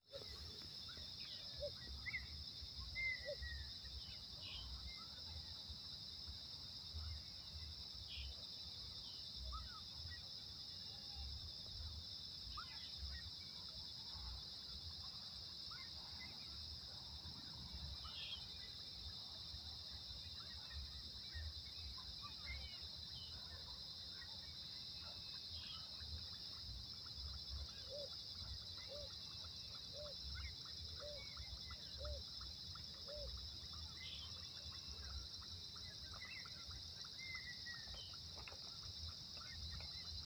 Red-winged Tinamou (Rhynchotus rufescens)
Condition: Wild
Certainty: Recorded vocal